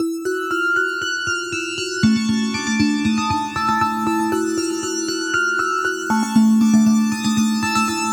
• Essentials Key Pop 1 118 bpm.wav